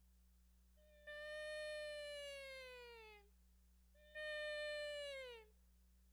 7) Simulating bone-transmission sound
I think it places somewhere between air and bone-conducted sounds.